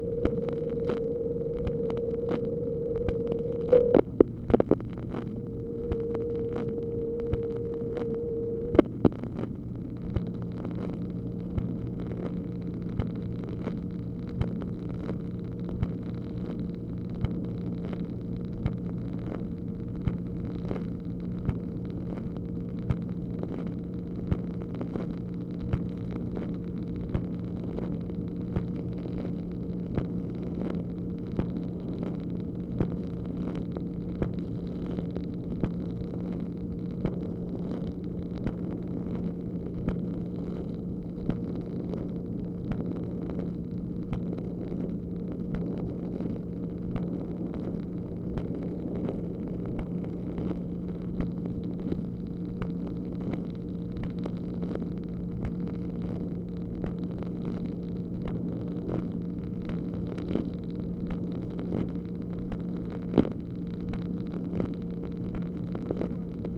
MACHINE NOISE, March 18, 1965